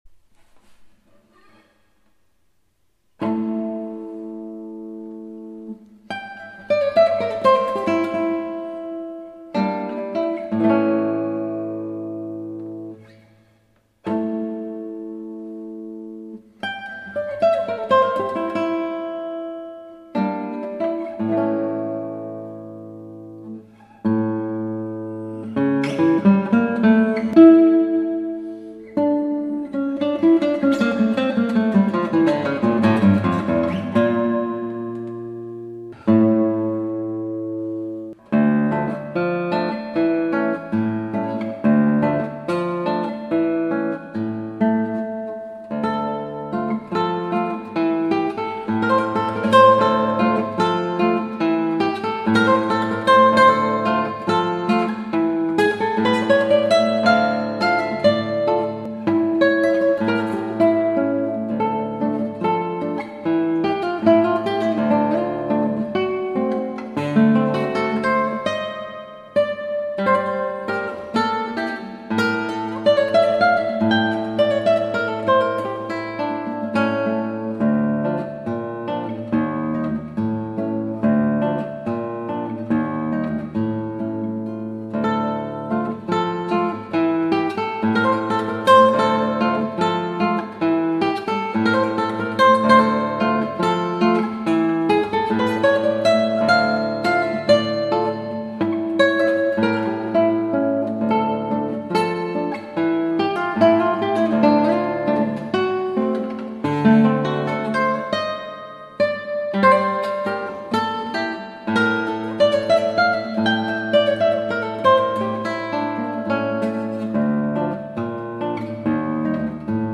アラビア風奇想曲、尾野ギターで